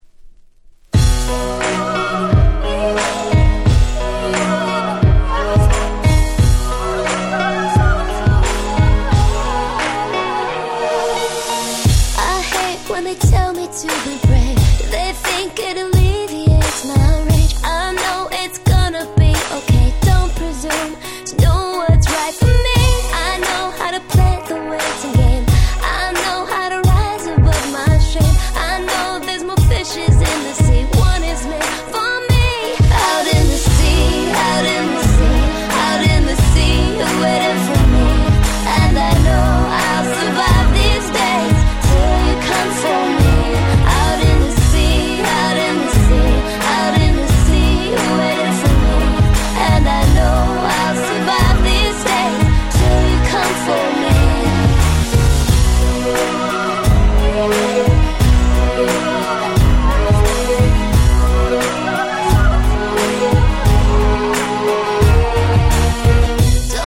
08' Nice EU R&B !!
キャッチー系